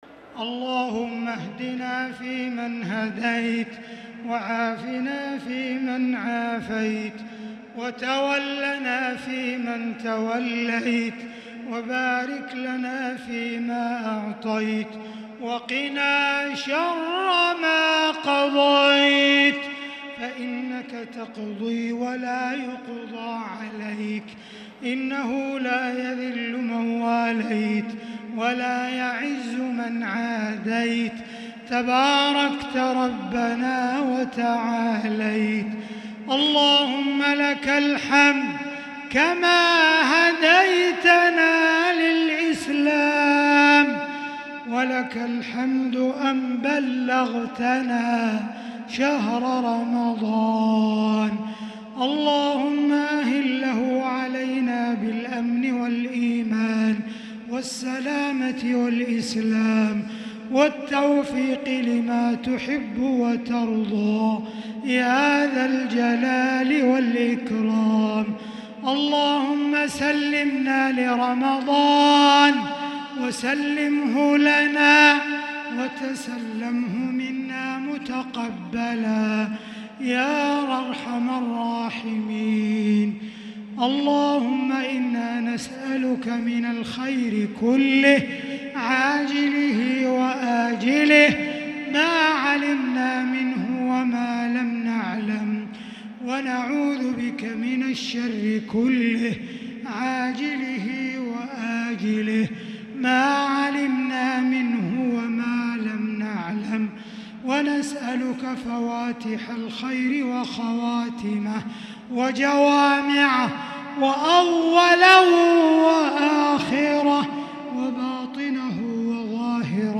دعاء القنوت ليلة 1 رمضان 1443هـ Dua 1st night Ramadan 1443H > تراويح الحرم المكي عام 1443 🕋 > التراويح - تلاوات الحرمين